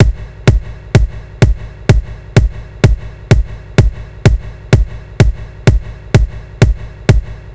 Bd Loop Ghetto.wav